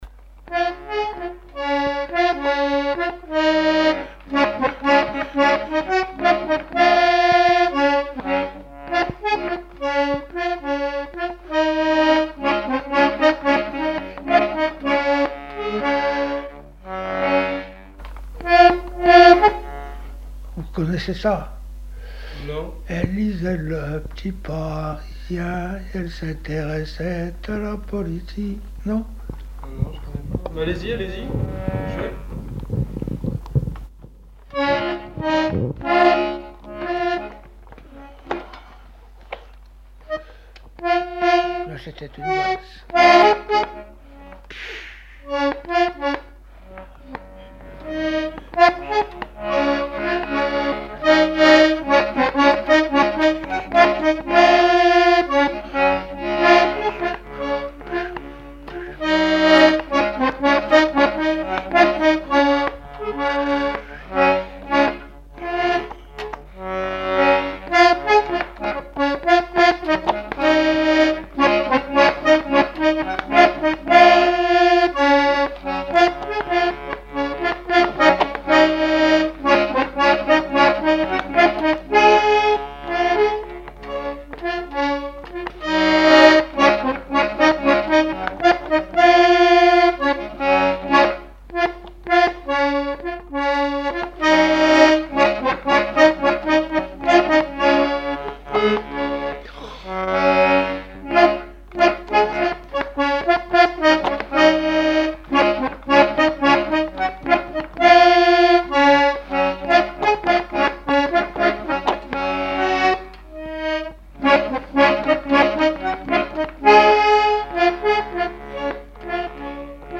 Genre strophique
Répertoire instrumental à l'accordéon diatonique
Pièce musicale inédite